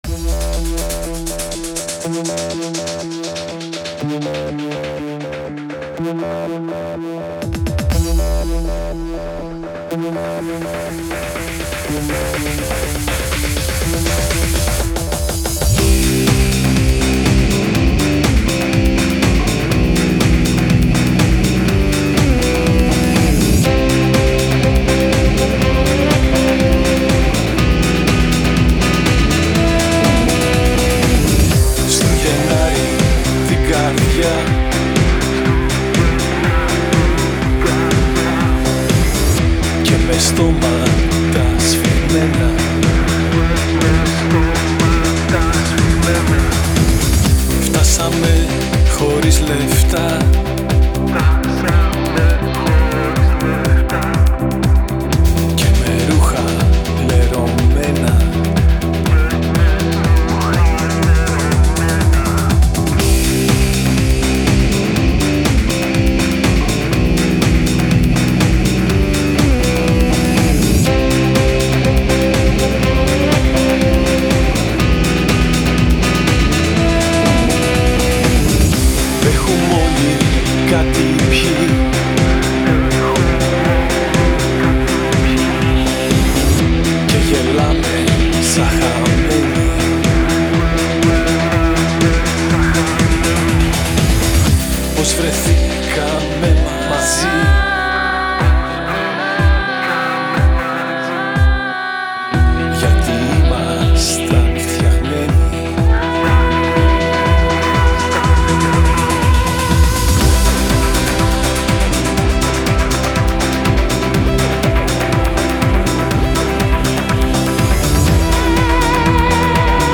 κιθάρα, μπάσο, πλήκτρα
Κρουστά
Σαξόφωνο
τύμπανα
φλάουτο